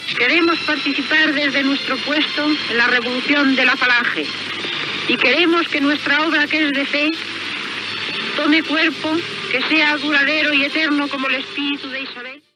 Paraules de Pilar Primo de Rivera
Paraules pronunciades el dia de la inauguració del II Consejo Nacional de la Sección Femenina de Falange Española Tradicionalista (FET) y de las Juntas de Ofensiva Nacional-Sindicalista (JONS), celebrat a Segovia.
Fragment extret del programa "La radio con botas", de Radio 5 (RNE), emès l'any 1991. Procedent d'un documental produït per la Sección Cinematográfica de FET y de las JONS (1938)